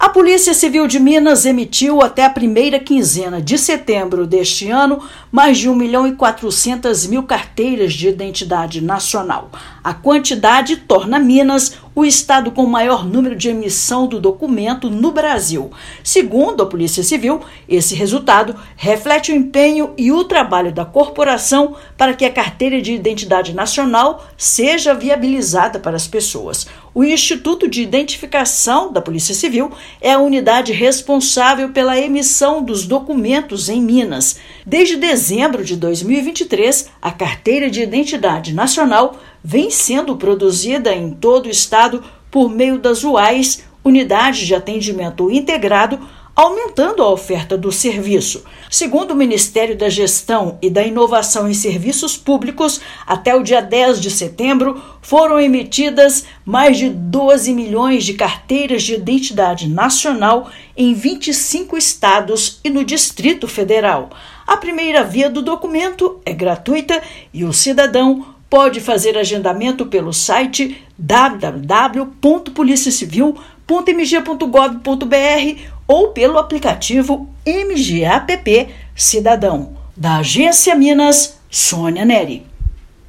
Novo documento de identidade vem sendo expedido no estado desde dezembro de 2023. Ouça matéria de rádio.